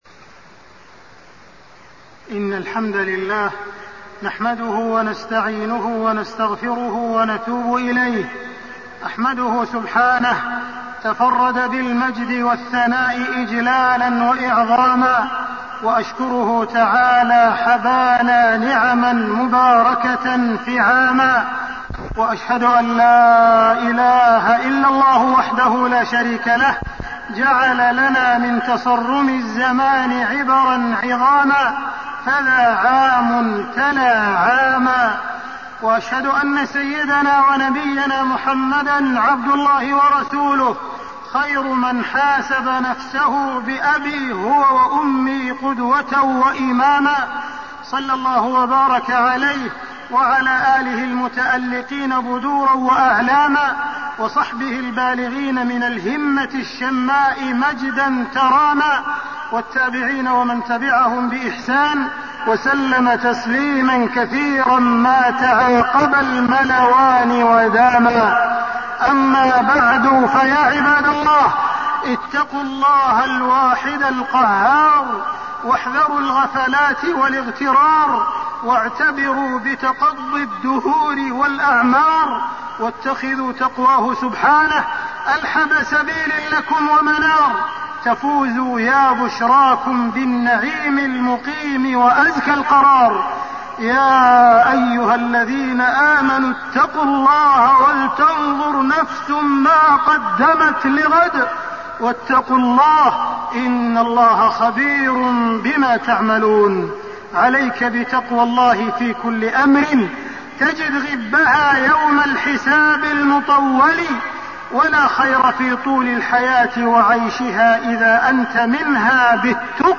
تاريخ النشر ٤ محرم ١٤٣٢ هـ المكان: المسجد الحرام الشيخ: معالي الشيخ أ.د. عبدالرحمن بن عبدالعزيز السديس معالي الشيخ أ.د. عبدالرحمن بن عبدالعزيز السديس الاعتبار بانقضاء الأعمار